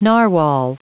Prononciation du mot narwhal en anglais (fichier audio)
Prononciation du mot : narwhal
narwhal.wav